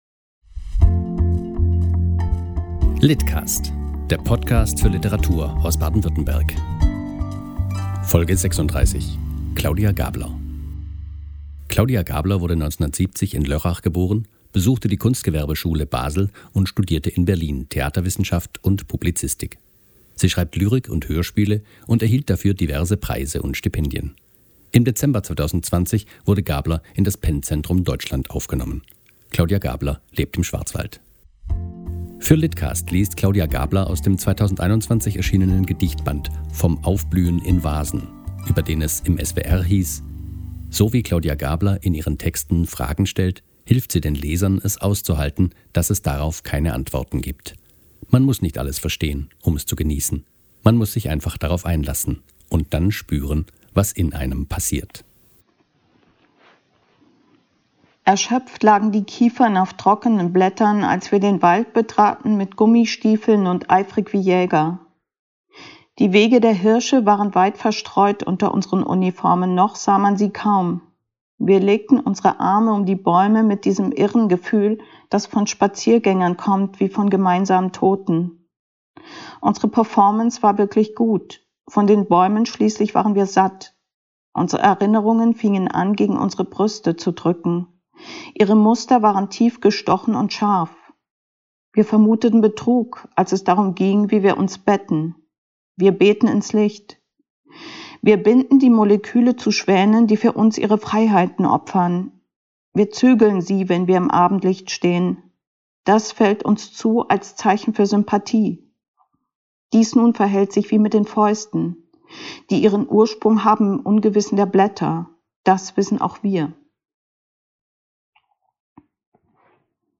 liest aus "Vom Aufblühen in Vasen"